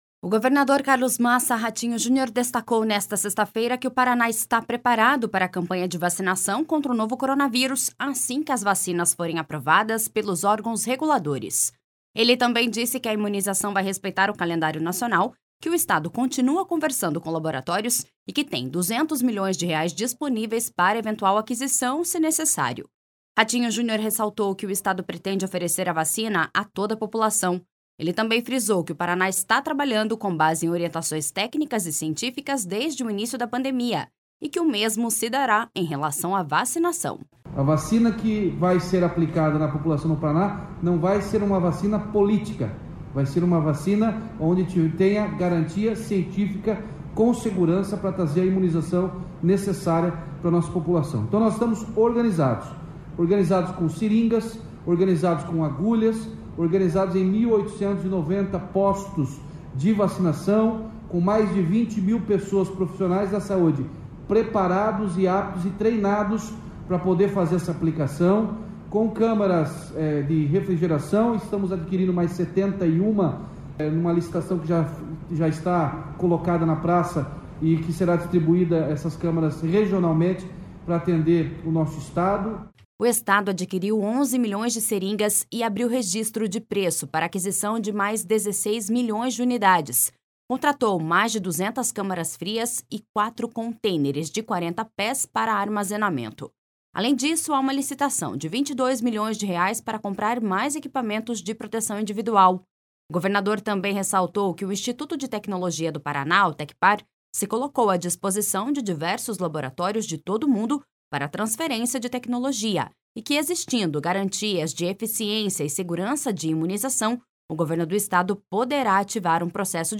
Ele também frisou que o Paraná está trabalhando com base em orientações técnicas e científicas desde o início da pandemia e que o mesmo se dará em relação à vacinação.// SONORA RATINHO JUNIOR.//
Ele citou que todas as 399 cidades terão acesso ao imunizante e precisam estar preparadas para o momento da vacinação.// SONORA BETO PRETO.//